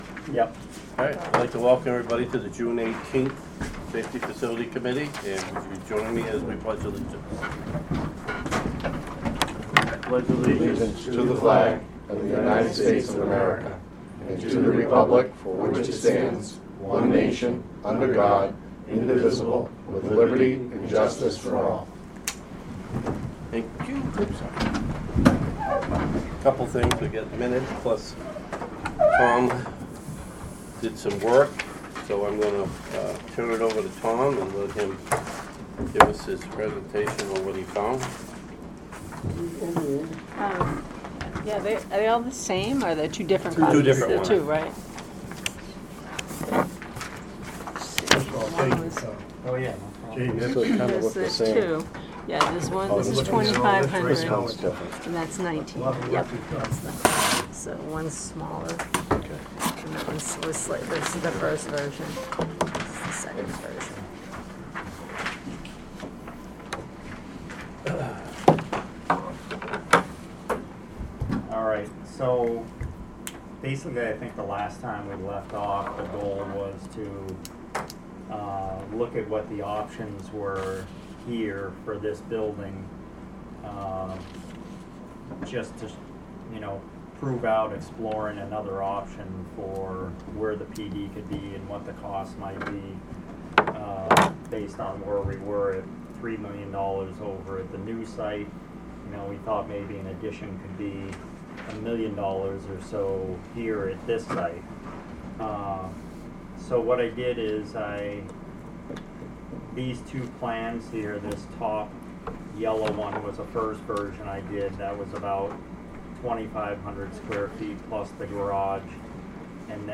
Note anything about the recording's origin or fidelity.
Audio recordings of committee and board meetings.